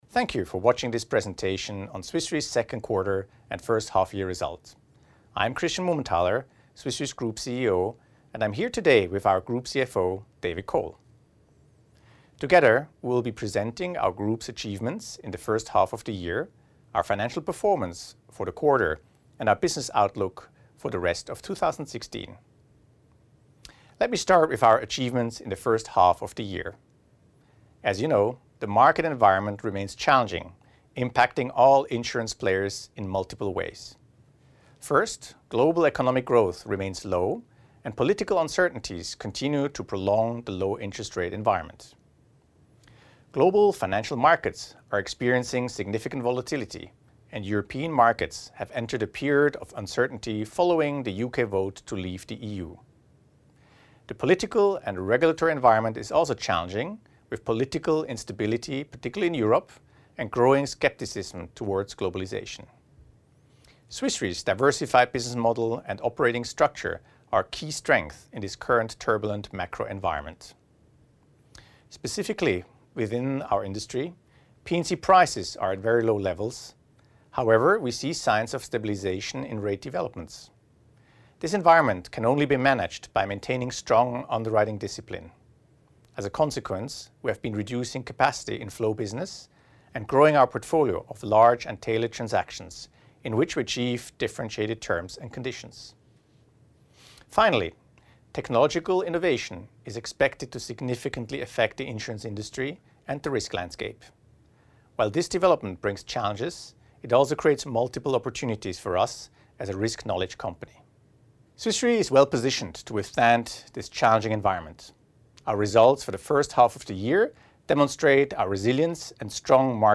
Audio of Second Quarter 2016 results Video Presentation